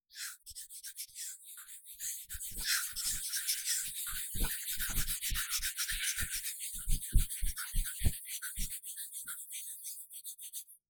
I am more concerned with impercetible sounds in my recordings and this was my focus during the workshop, although I did record some sounds in the stairwell and then some smaller sounds.
ste-046-noise-reduction-duplicate-binaural.wav